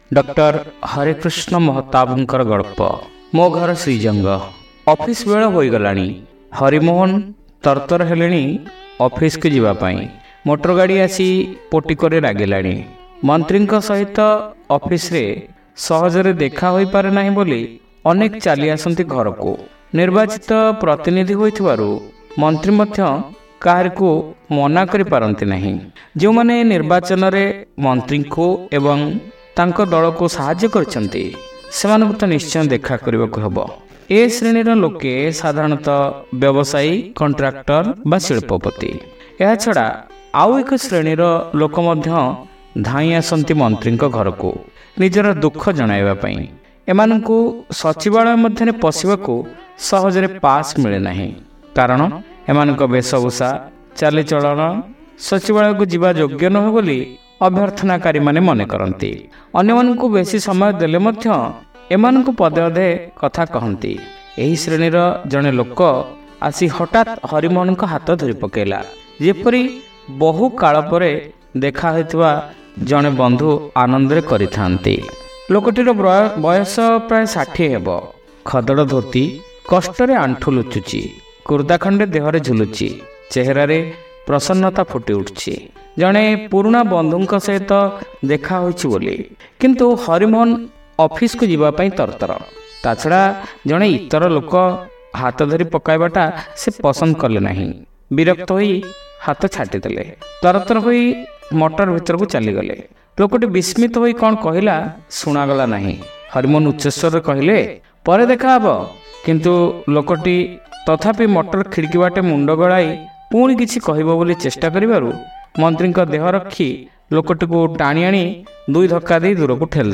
Audio Story : Mo Ghara Srijanga